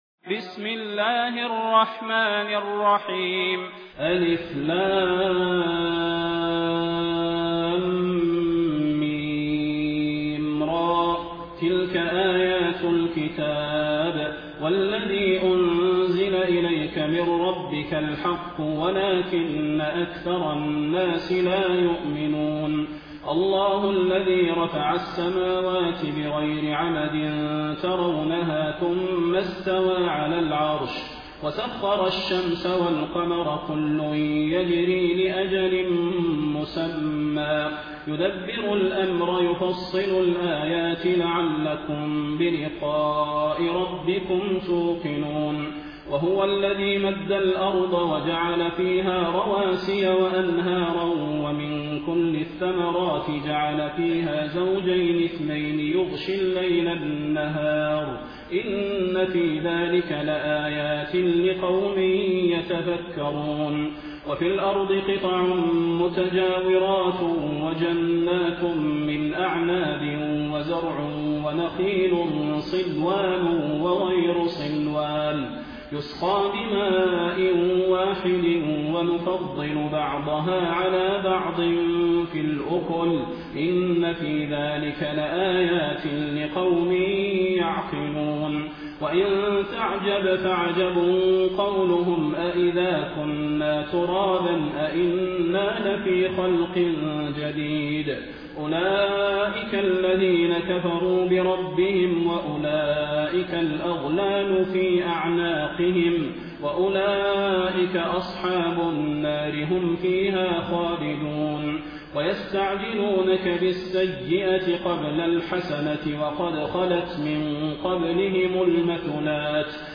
Quran recitations
taraweeh-1433-madina